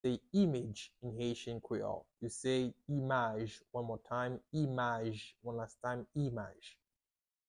How to say "Image" in Haitian Creole - "Imaj" pronunciation by a native Haitian teacher
“imaj” Pronunciation in Haitian Creole by a native Haitian can be heard in the audio here or in the video below:
How-to-say-Image-in-Haitian-Creole-Imaj-pronunciation-by-a-native-Haitian-teacher.mp3